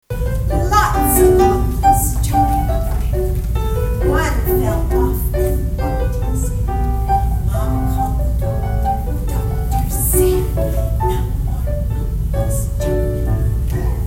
The community hall at the myFM Centre was filled with pretty music, smiling family members and the cutest little ballerinas as the Town of Renfrew’s youth ballet program wrapped up for the season Thursday.
may-16-ballet-monkeys.mp3